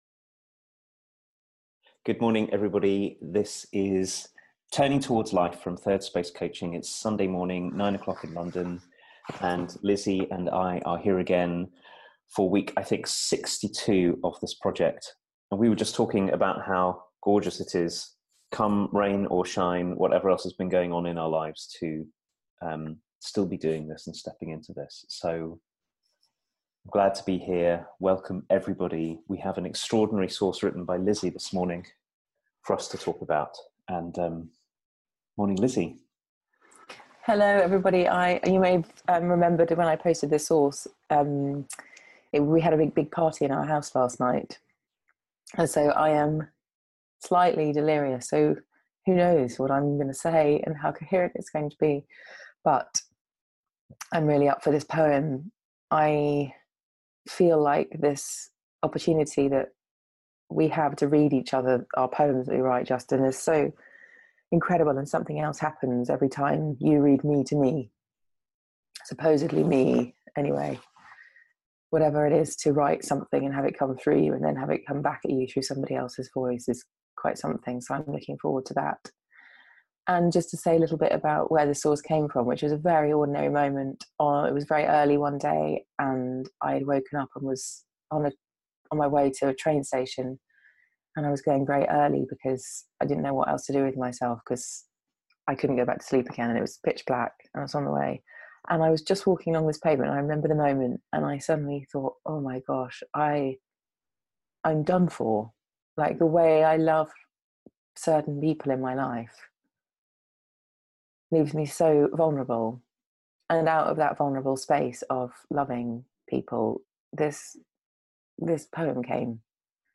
Love, and grief, and defendedness, and the huge life-giving letting go (and welcome) that's required for us to love and be loved in return. A conversation